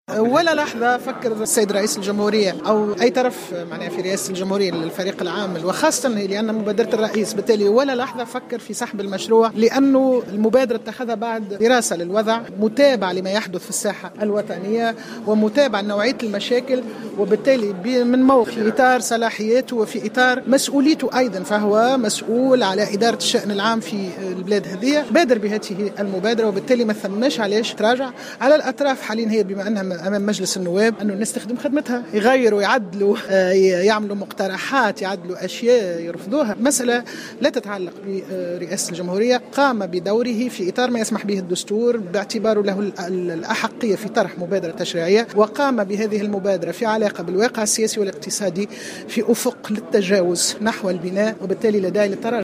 تصريح
خلال ندوة وطنية نظمتها جمعية البرلمانيين التونسيين حول موضوع المصالحة كخيار استراتيجي لتعزيز الوحدة الوطنية